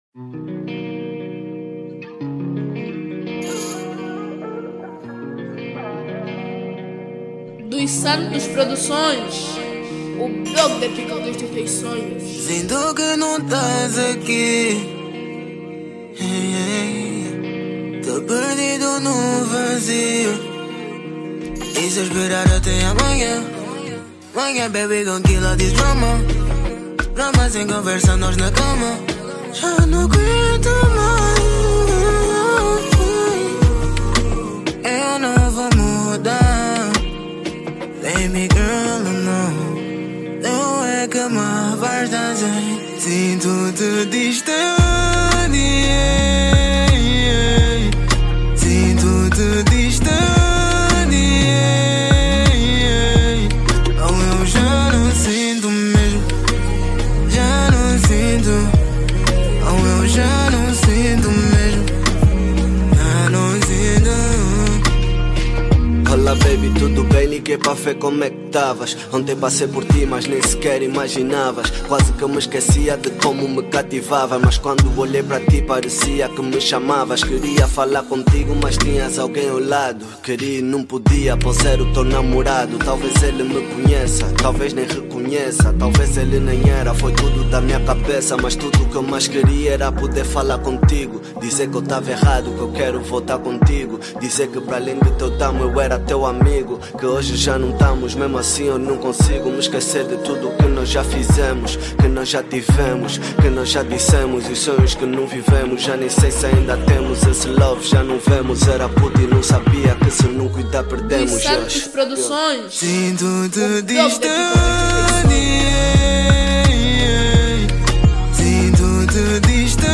Categoria  RnB